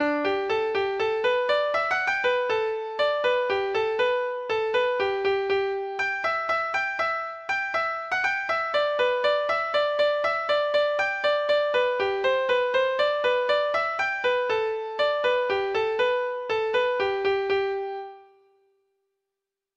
Folk Songs from 'Digital Tradition' Letter T There Was a Wee Wifey
Free Sheet music for Treble Clef Instrument